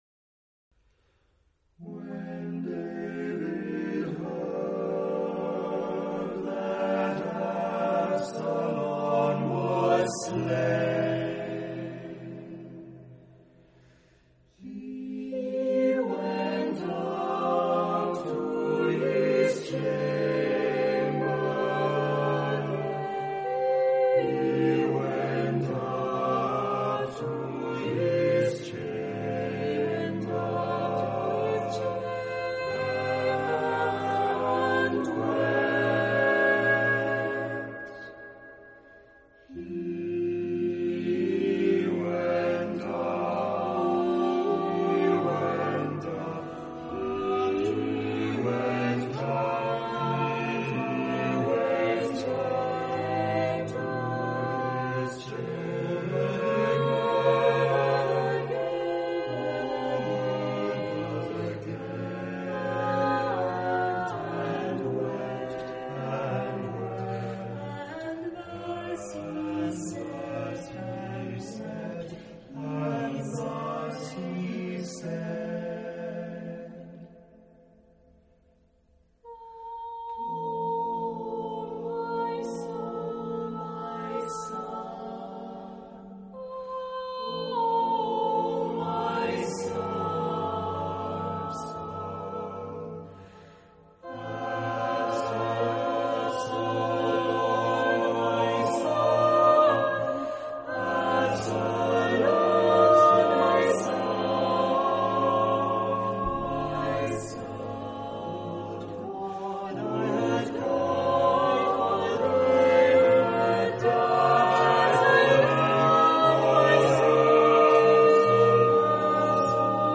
Genre-Stil-Form: geistlich ; Chor
Chorgattung: SSAATTBB  (4 gemischter Chor Stimmen )
Tonart(en): f-moll